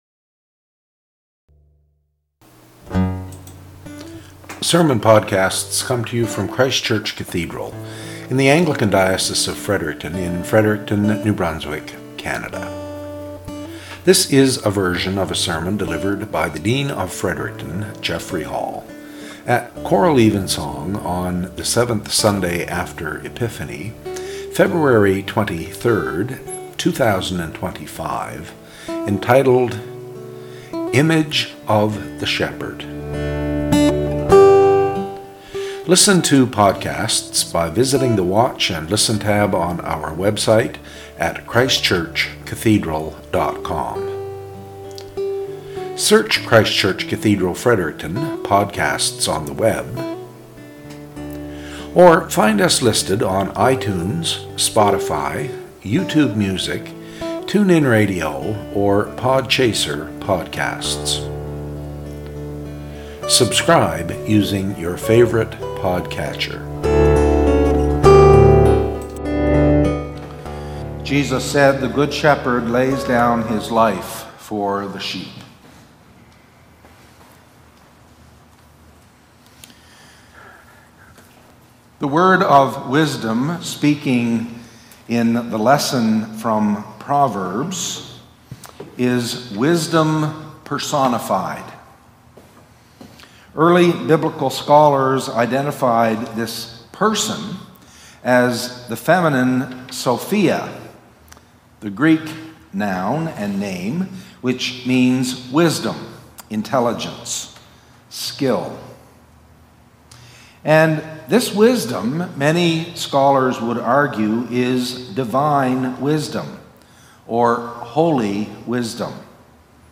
SERMON - "Image of the Shepherd"
Lections for Evensong Proper 7 Year 1